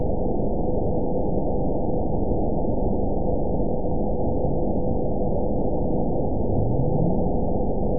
event 920358 date 03/18/24 time 05:23:42 GMT (1 year, 1 month ago) score 9.59 location TSS-AB01 detected by nrw target species NRW annotations +NRW Spectrogram: Frequency (kHz) vs. Time (s) audio not available .wav